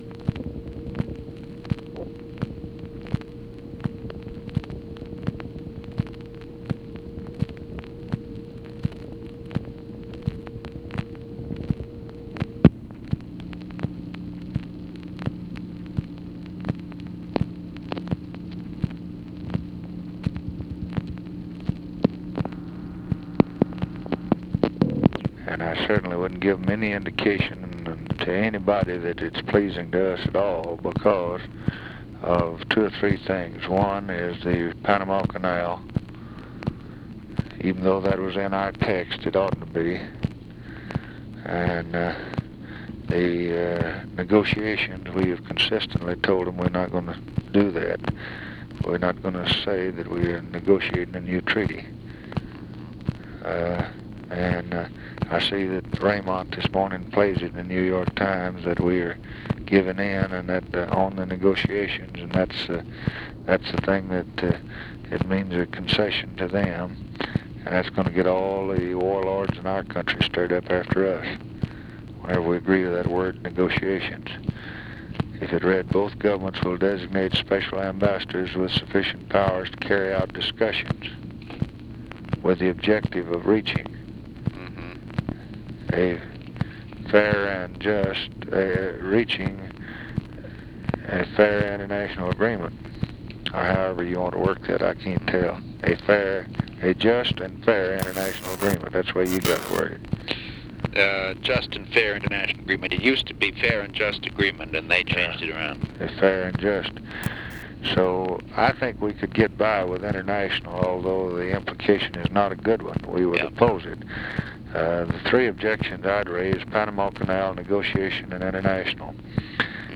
Conversation with MCGEORGE BUNDY, March 10, 1964
Secret White House Tapes